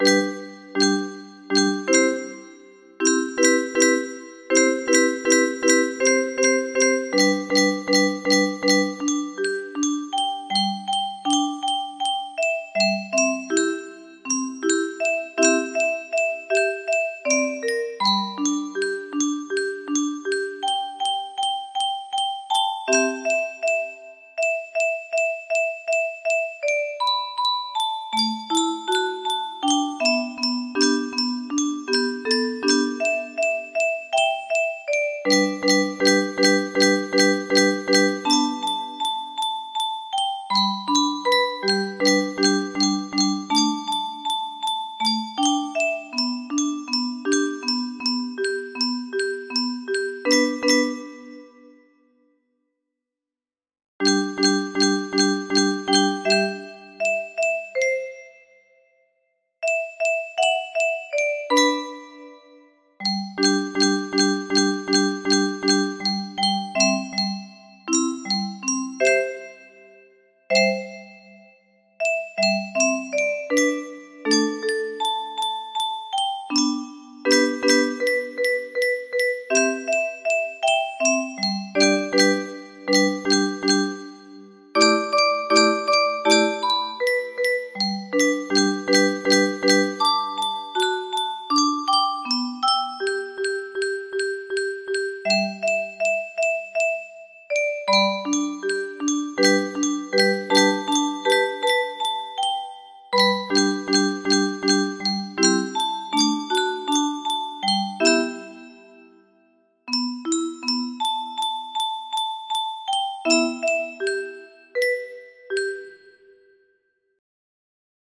Unknown Artist - Untitled music box melody
Imported from MIDI from imported midi file (2).mid